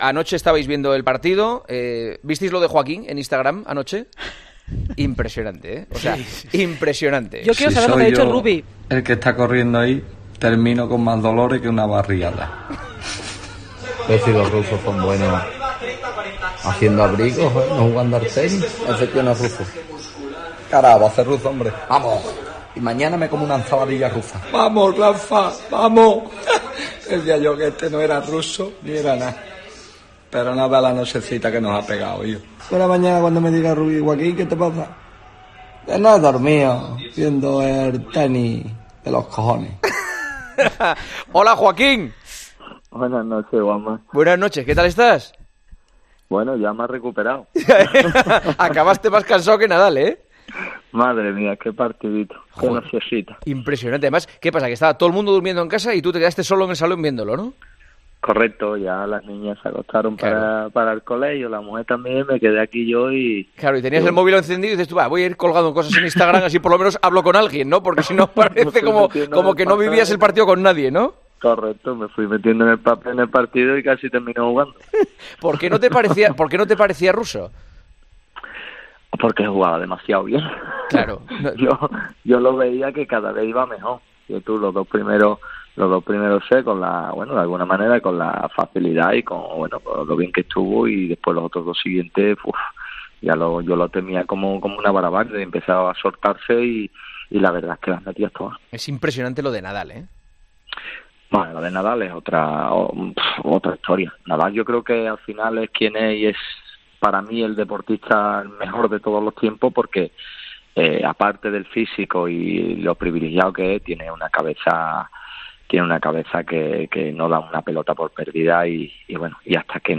Joaquín Sánchez, jugador del Real Betis Balompié, ha pasado este lunes por los micrófonos de 'El Partidazo de COPE' para contar en primera persona cómo vivió la agónica final del US Open entre Rafa Nadal y Daniil Medvédev.
Si me duermo con la raqueta la mujer me hubiera tirado”ha expresado entre risas el futbolista en 'El Partidazo de COPE'.